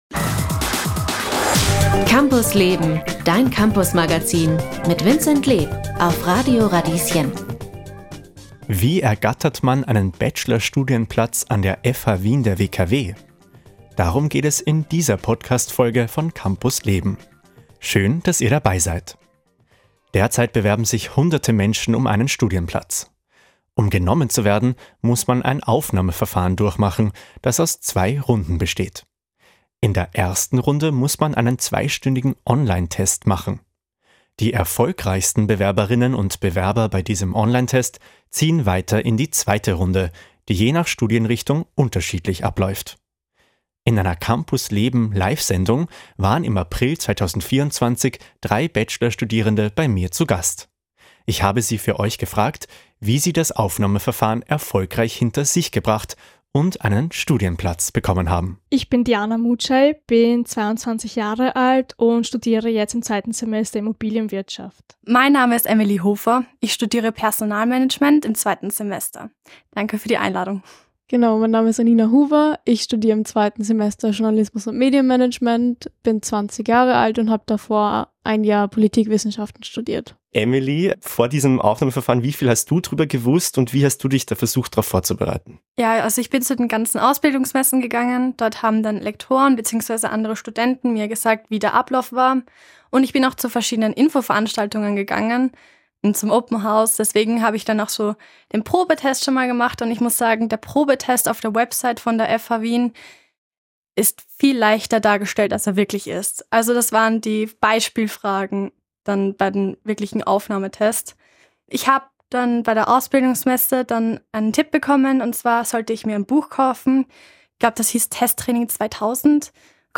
Die Ausschnitte stammen aus einer Live-Sendung vom April 2024.